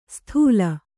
♪ sthūla